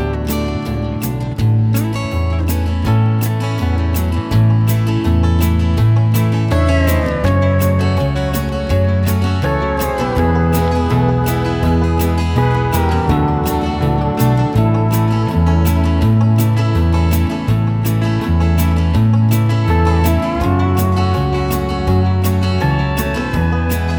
Chorus Backing Vocals Only Country (Male) 3:09 Buy £1.50